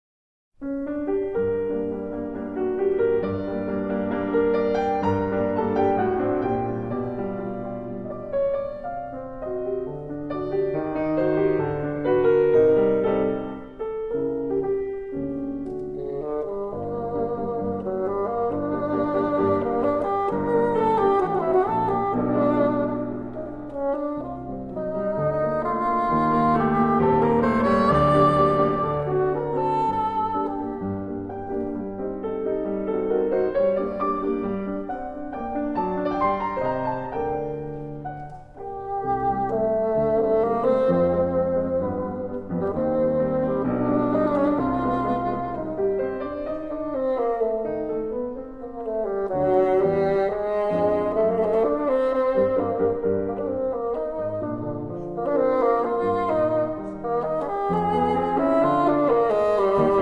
Sonata for Bassoon and Piano   9:05
piano and harpsichord